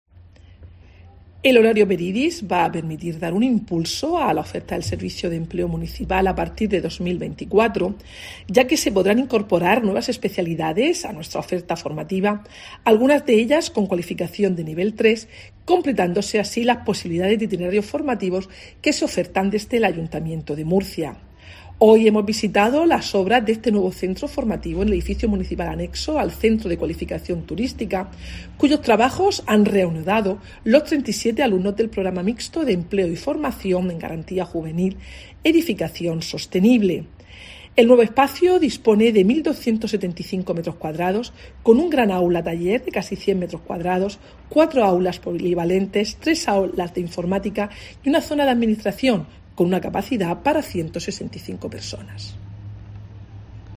Mercedes Bernabé, concejal de Gobierno Abierto, Promoción Económica y Empleo